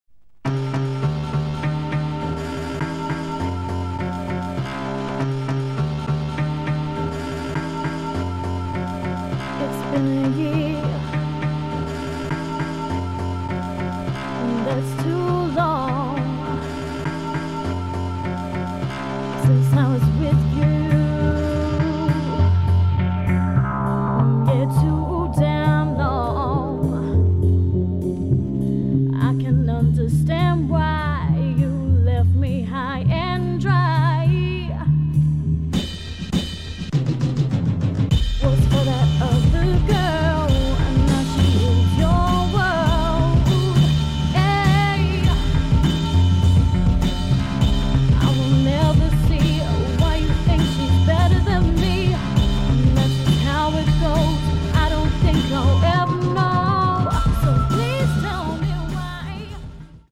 sweet soul vocalist